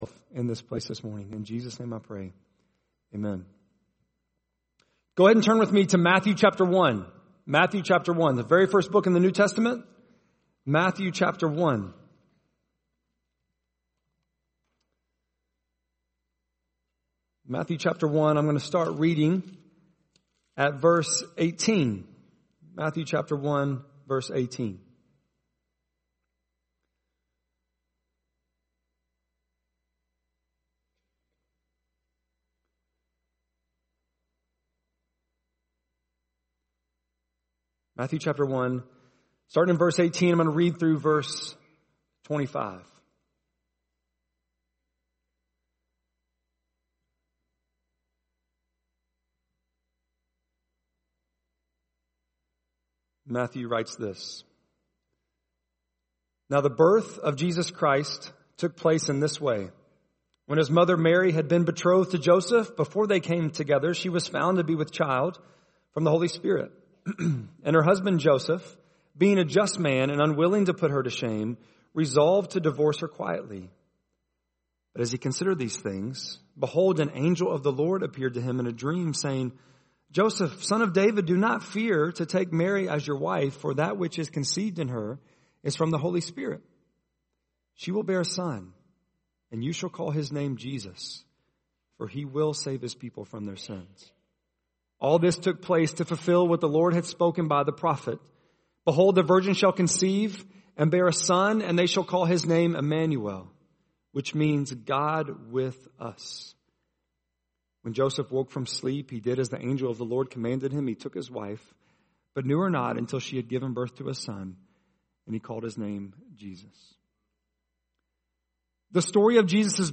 11.28-sermon.mp3